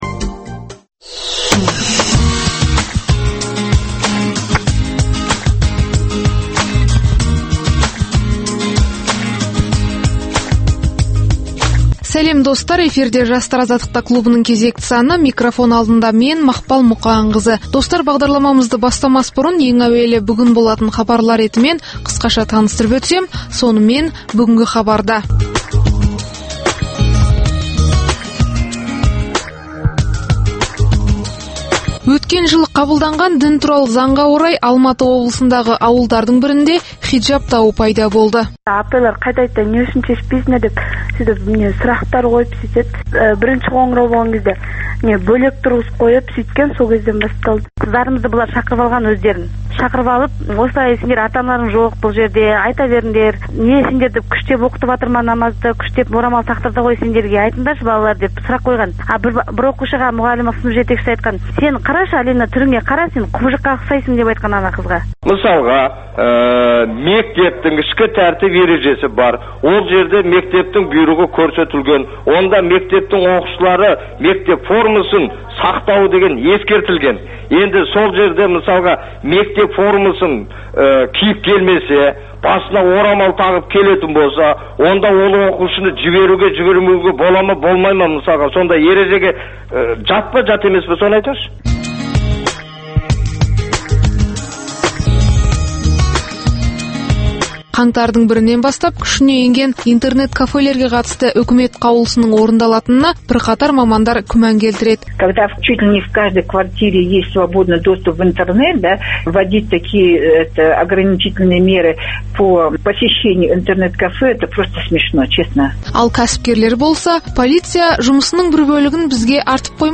Қазақстанда әр уақытта өткізілетін ақындар айтысының толық нұсқасын ұсынамыз.